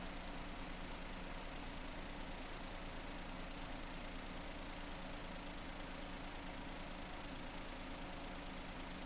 ベルト鳴き止めスプレーを使用する前後にボンネットを開けて
騒音計（平坦特性）とデータレコーダにてエンジン稼働音を収録
使用後は、高音成分が減少し、近所
迷惑にならない程度の騒音（70dB）になった。
メンテナンス後は、1ｋHzから8ｋHzと周波数が高くなるほど
メンテナンス前後の音
belt_after.mp3